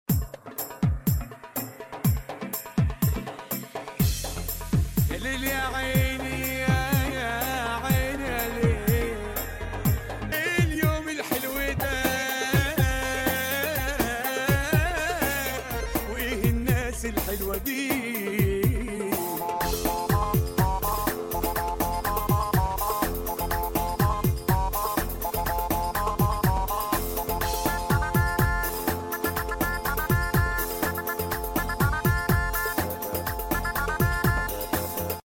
Mahraganat Party Remix